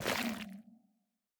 Minecraft Version Minecraft Version snapshot Latest Release | Latest Snapshot snapshot / assets / minecraft / sounds / block / sculk / step5.ogg Compare With Compare With Latest Release | Latest Snapshot
step5.ogg